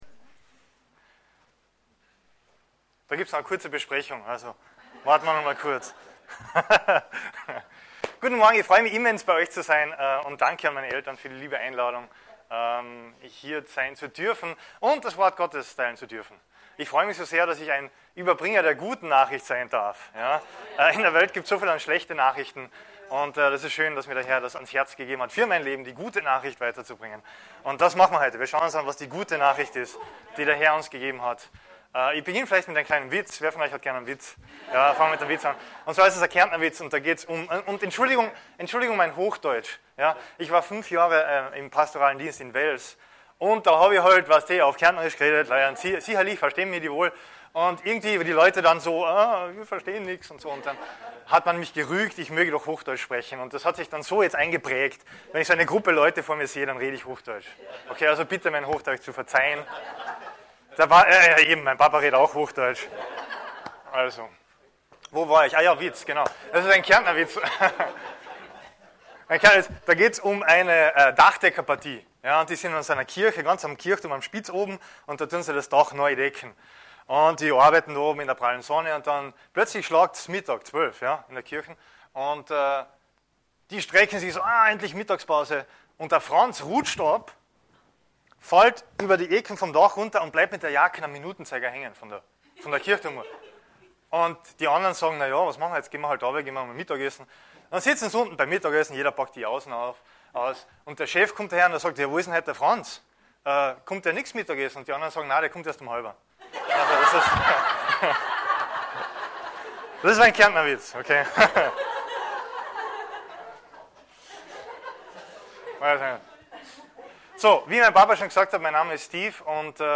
Anmerkung: Die Botschaft wurde 2012 aufgenommen und wir bitten die Tonstörungen zu entschuldigen.